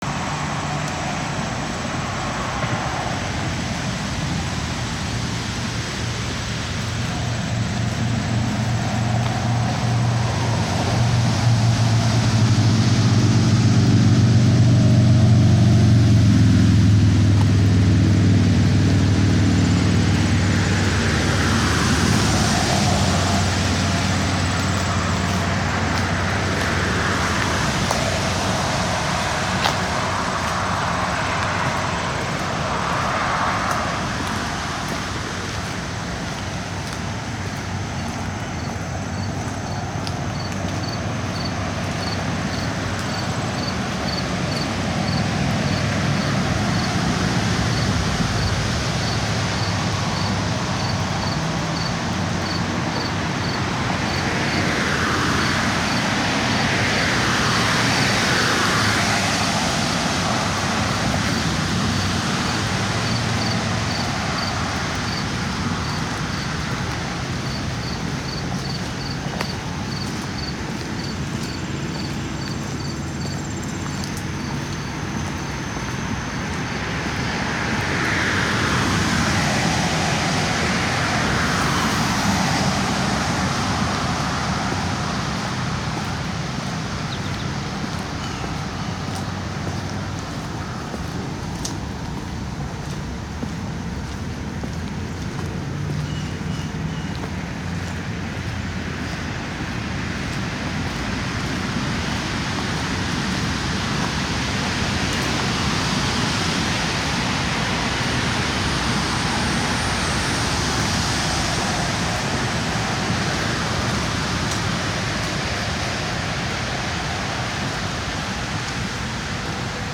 Mid-morning in late summer. This is a very urban/suburban area with lots of human sounds: traffic, airplanes, gardeners, etc. There are also insects and birds. My footsteps on the path are also audible.
Posted: in Field-recordings.
Tags only on this post: urban.